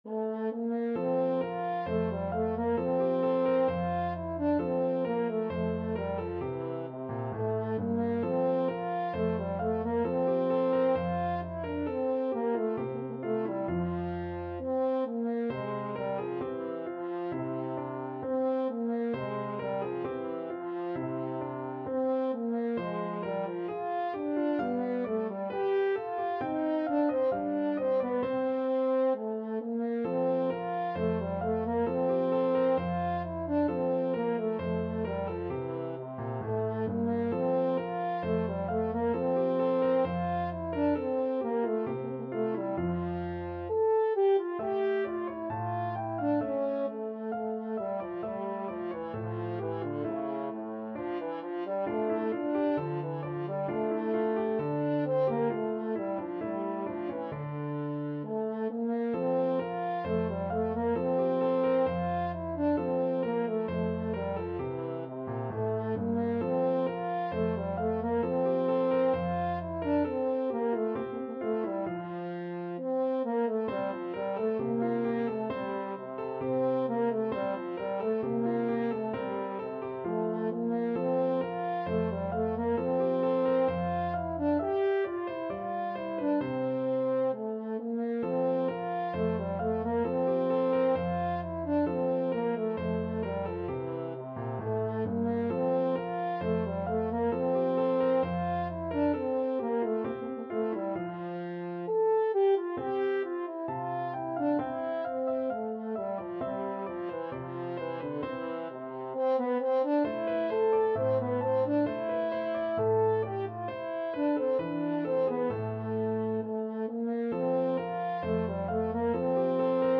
French Horn
2/2 (View more 2/2 Music)
F major (Sounding Pitch) C major (French Horn in F) (View more F major Music for French Horn )
~ = 100 Allegretto =c.66
Classical (View more Classical French Horn Music)